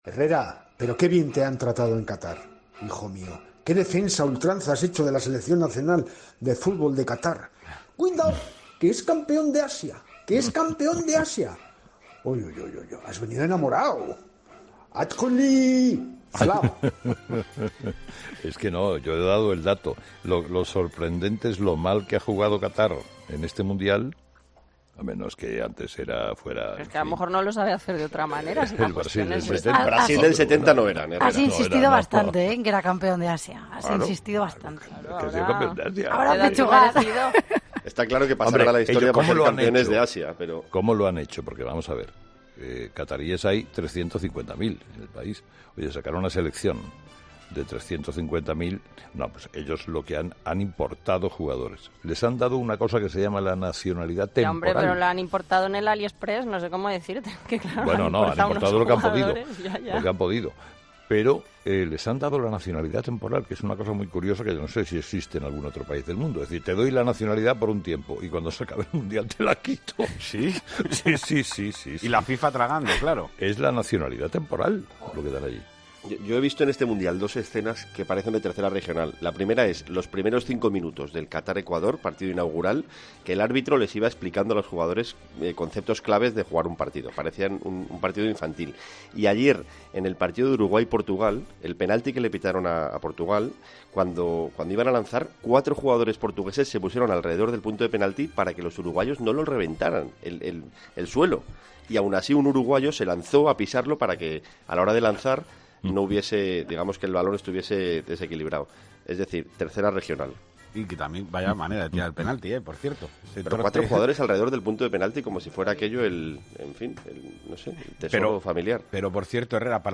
Escucha a Carlos Herrera explicar por qué un equipo de La Liga ganaría el Mundial de Qatar